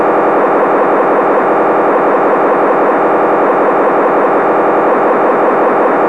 Sample canale di controllo Motorola TypeII